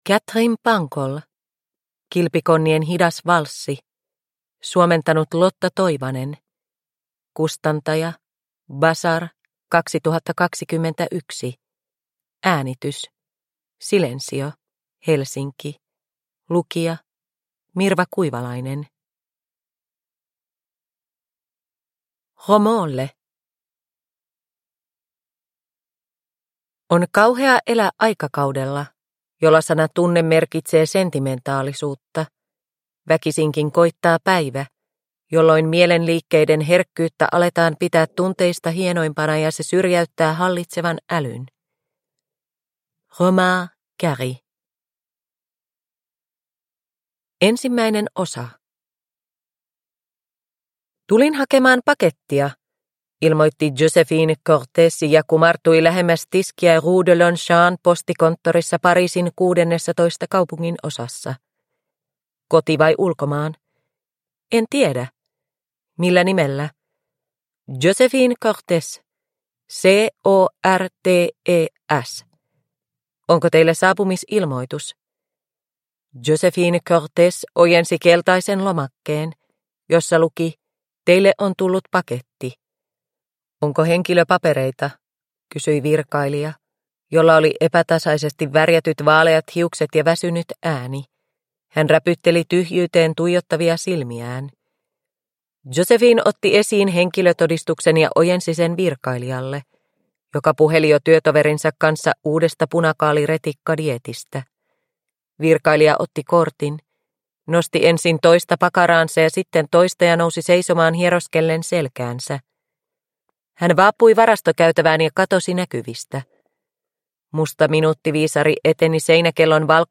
Kilpikonnien hidas valssi – Ljudbok – Laddas ner